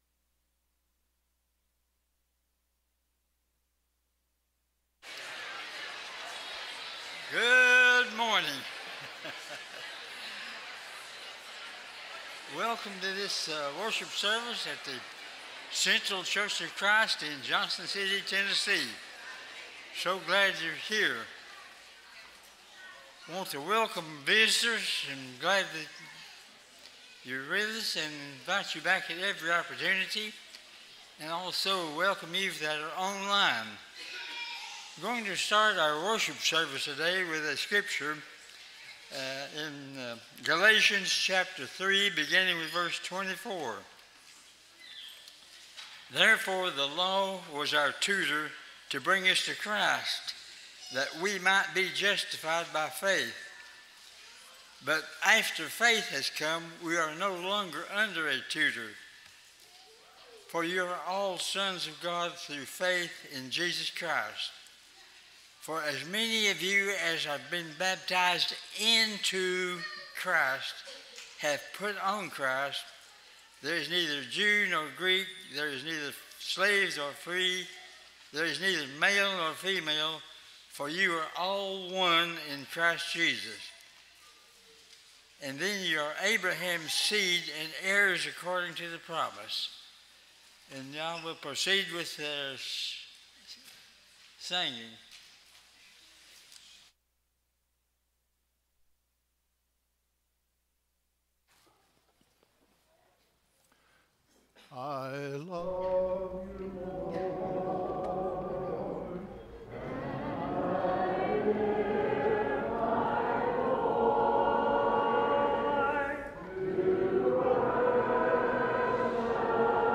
That is why it is through Him that we utter our Amen to God for His glory.” 2 Corinthians 1:20, English Standard Version Series: Sunday AM Service